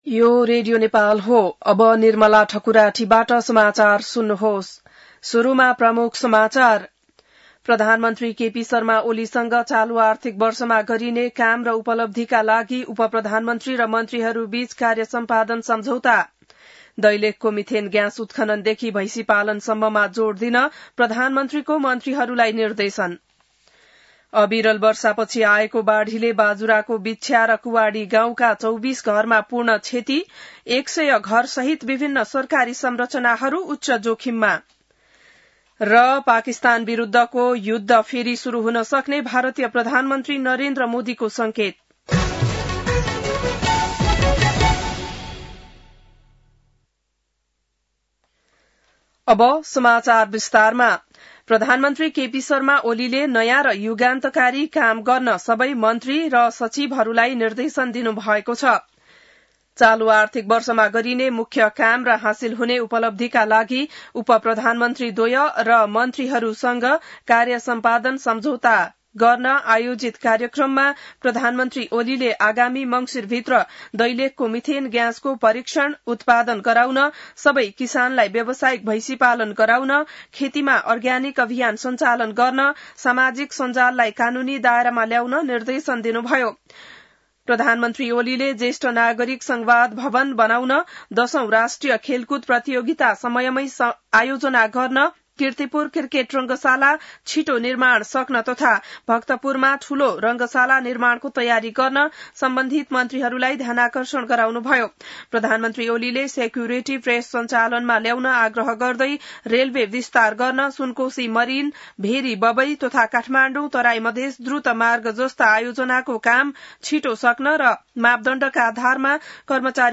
बिहान ९ बजेको नेपाली समाचार : ३० साउन , २०८२